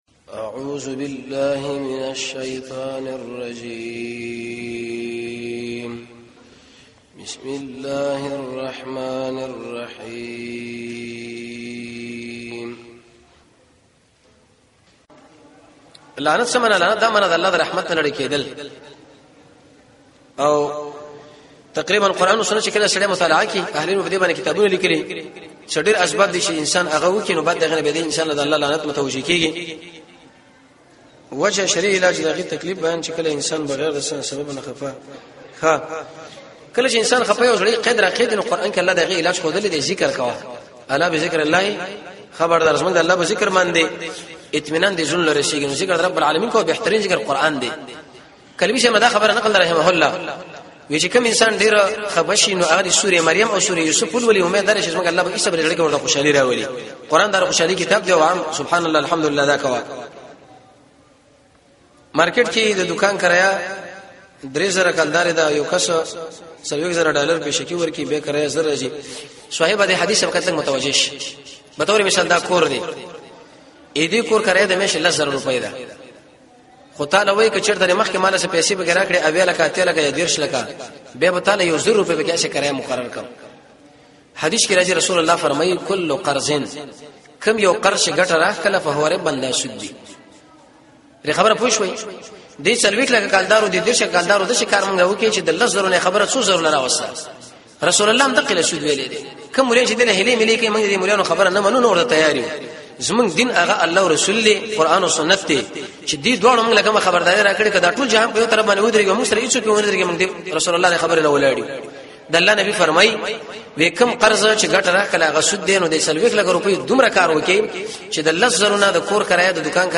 ۱ - پوښتنه او ځواب